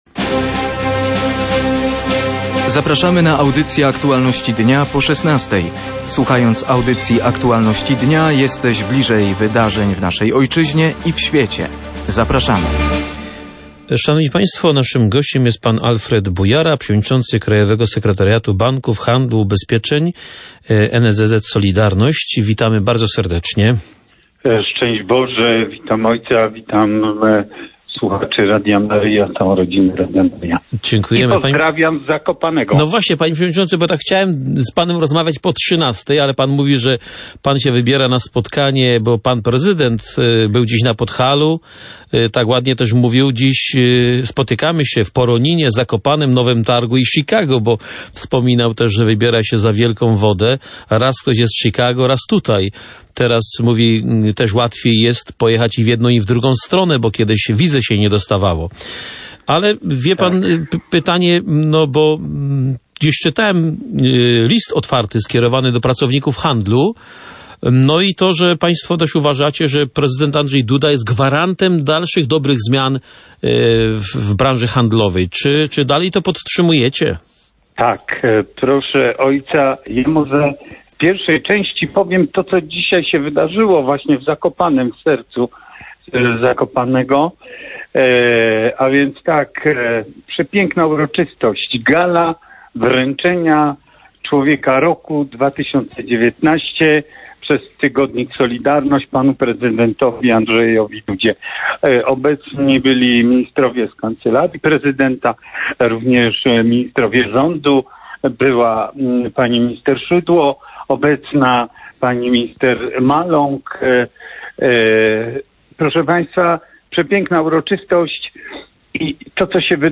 mówił we wtorkowych „Aktualnościach dnia” na antenie Radia Maryja